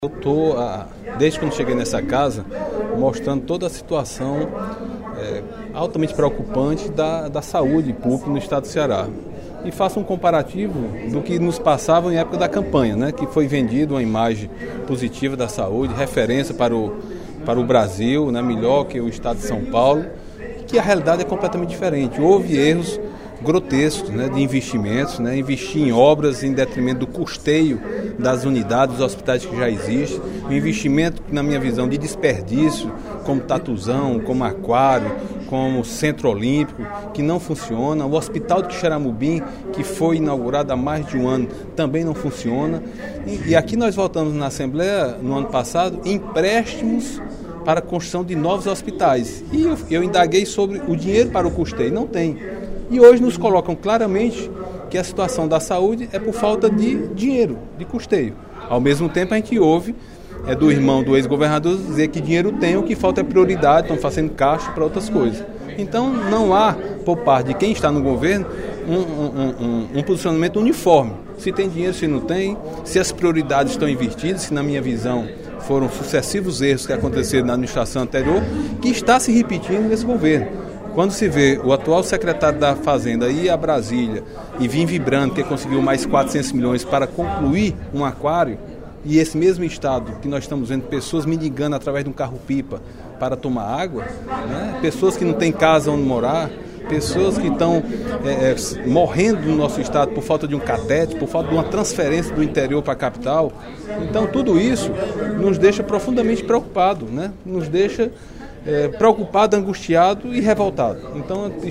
O deputado Agenor Neto (PMDB) lamentou, no primeiro expediente da sessão plenária desta quarta-feira (16/03), a situação da saúde pública no estado do Ceará, em especial da região centro-sul.